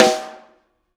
R_B Snare 05 - Close.wav